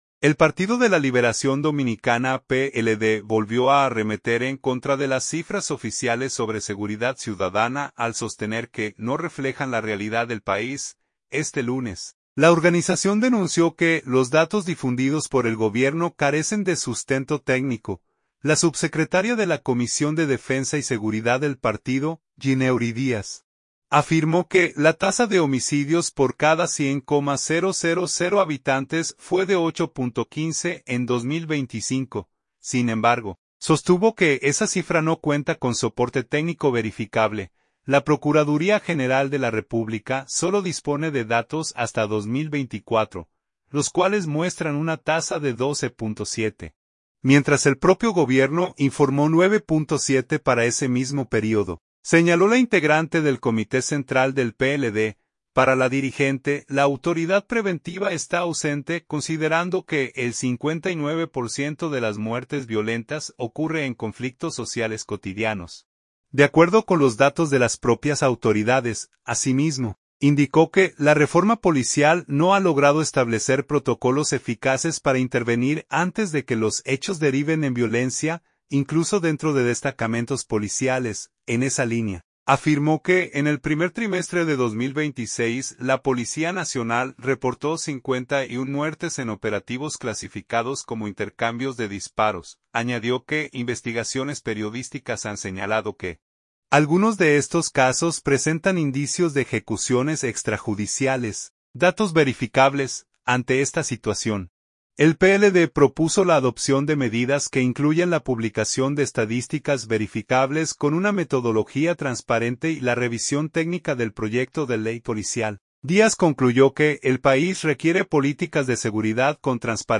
• Las declaraciones fueron ofrecidas durante una rueda de prensa realizada este lunes en la Casa Nacional del partido